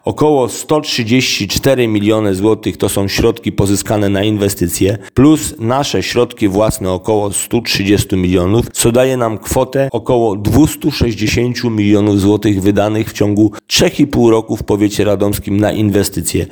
W tym roku znalazł się na czwartym miejscu wśród powiatów w województwie mazowieckim, a na dwudziestym pierwszym w całej Polsce. O funduszach pozyskanych na powiatowe inwestycje mówi starosta radomski Waldemar Trelka: